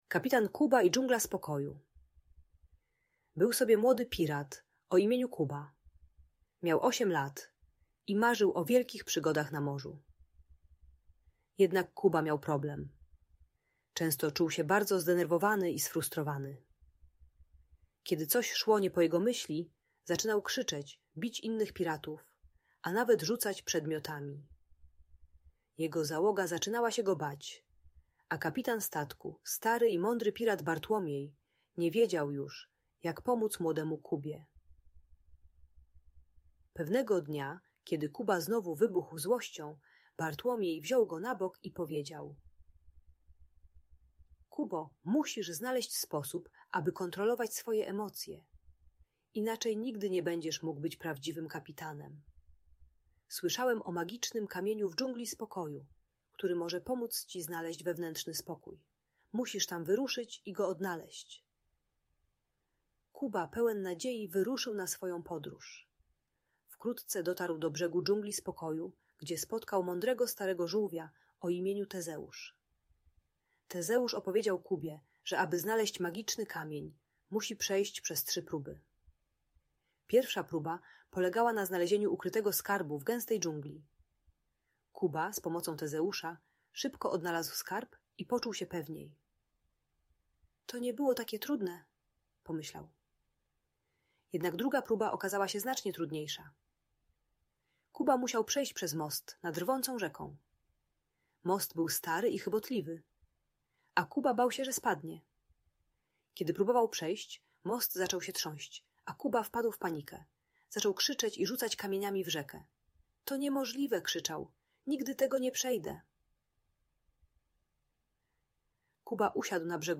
Kapitan Kuba i Dżungla Spokoju - story o emocjach - Audiobajka dla dzieci